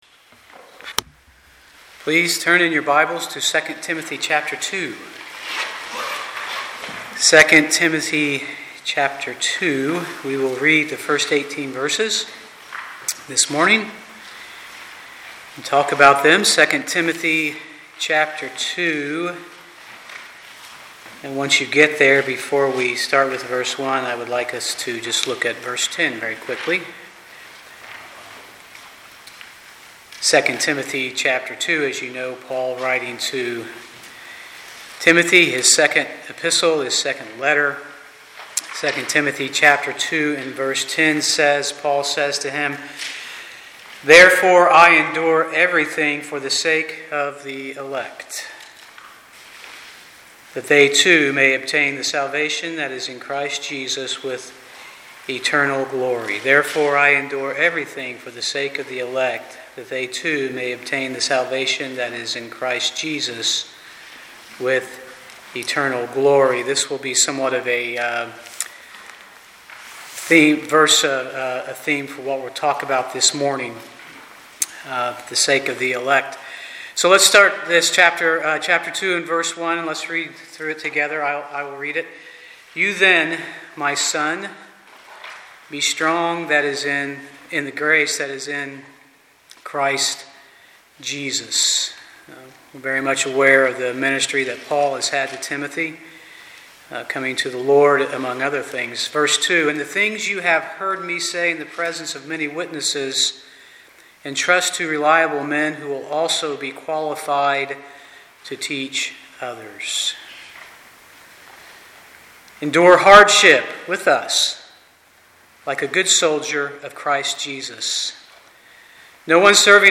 Passage: 2 Timothy 2:1-18 Service Type: Sunday morning